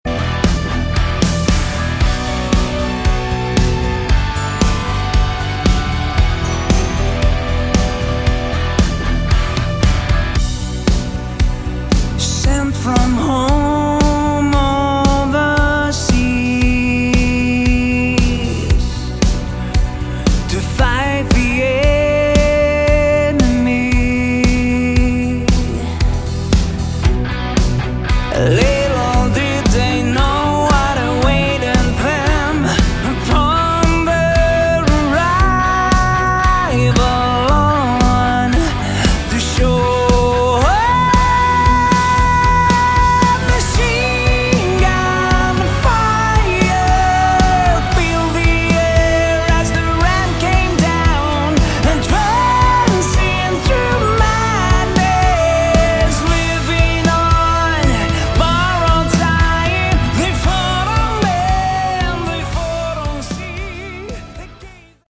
Category: AOR / Melodic Rock
Lead Vocals, Backing Vocals, Keyboards